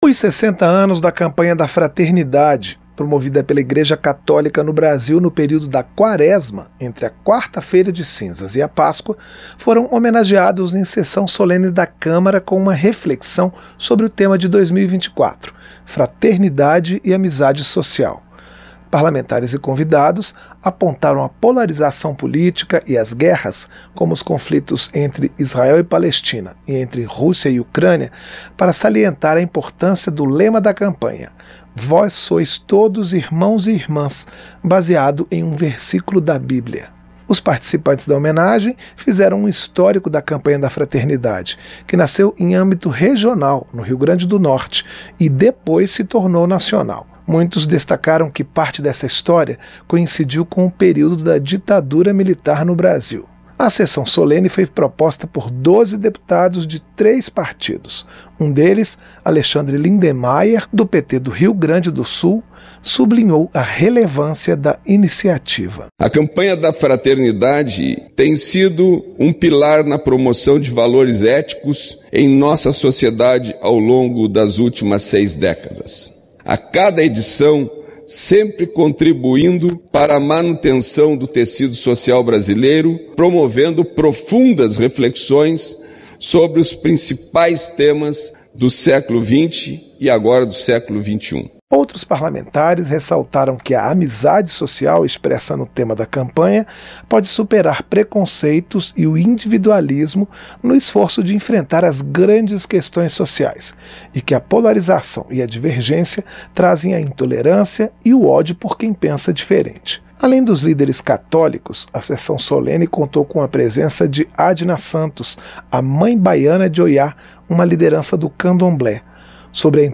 SESSÃO SOLENE HOMENAGEIA 60 ANOS DA CAMPANHA DA FRATERNIDADE E RESSALTA NECESSIDADE DE UNIÃO EM MEIO A GUERRAS E POLARIZAÇÃO.